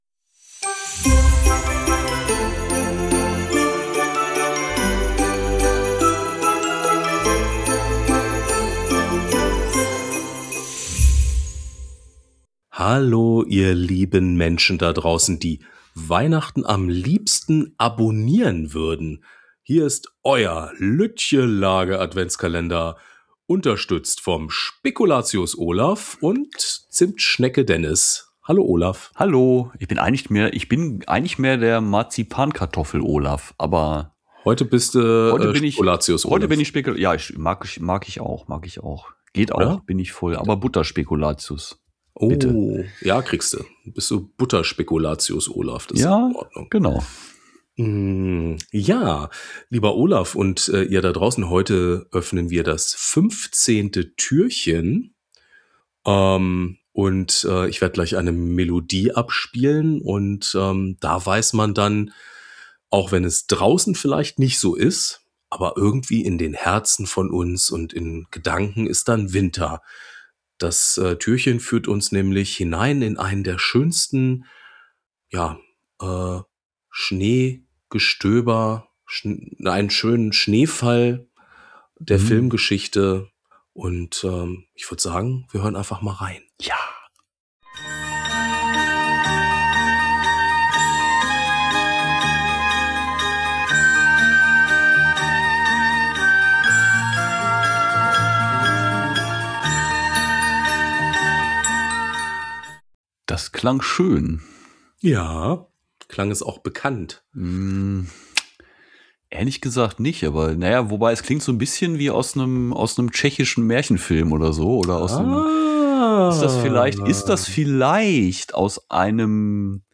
Retro, weihnachtlich, warm ums Herz: Euer täglicher Klangflash